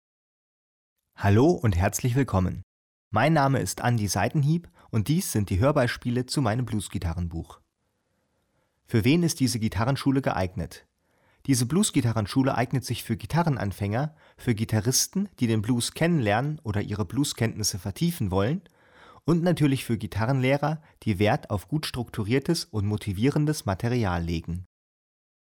Als MODERIERTE MP3-CD mit mehr als vier Stunden Spieldauer bietet sie präzise Anleitungen zu Fingersätzen, rhythmischen Besonderheiten und viele Playbacks zum Mitspielen.